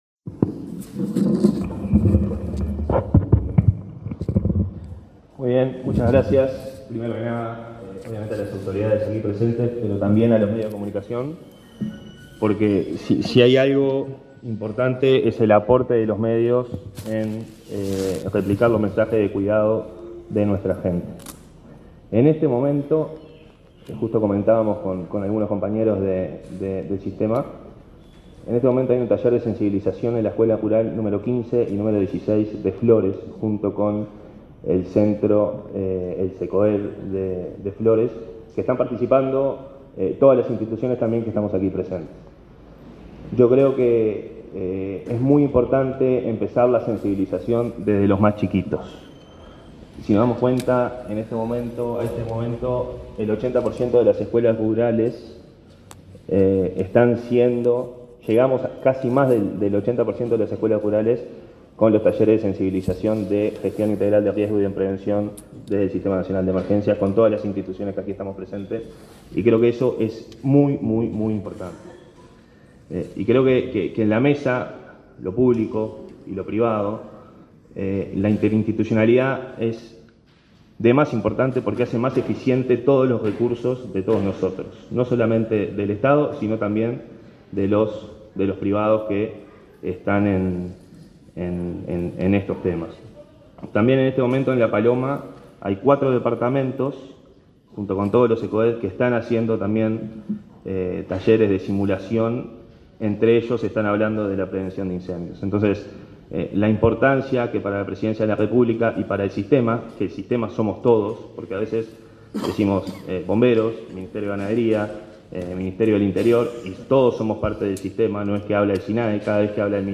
Palabra de autoridades en acto en Ministerio de Ganadería
Palabra de autoridades en acto en Ministerio de Ganadería 31/10/2023 Compartir Facebook X Copiar enlace WhatsApp LinkedIn Este martes 31, el director del Sistema Nacional de Emergencia (Sinae), Santiago Caramés; el director nacional de Bomberos, Ricardo Riaño, y el ministro de Ganadería, Fernando Mattos, participaron de una conferencia de prensa conjunta para informar acerca de acciones conjuntas de prevención de incendios forestales.